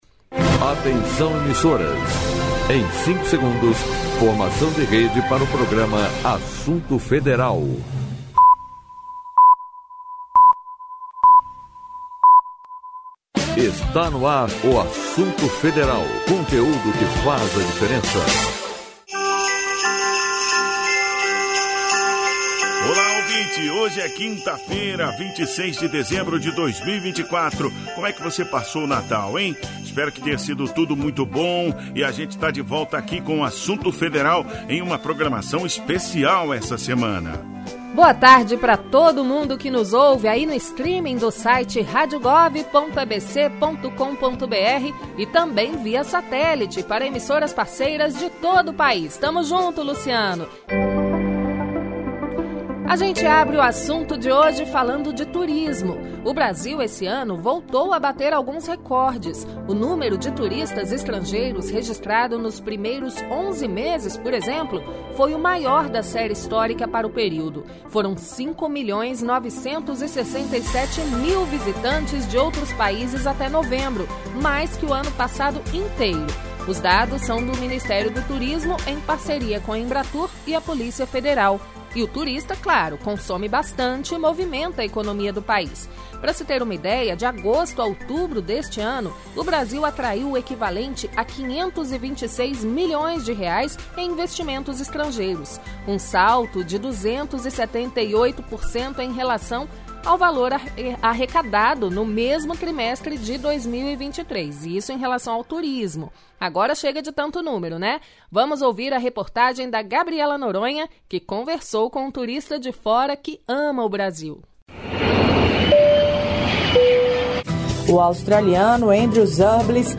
Acompanhe o programa completo desta quarta-feira (13) e fique bem informado; hoje também teve música no quadro 'O que Toca na Esplanada'.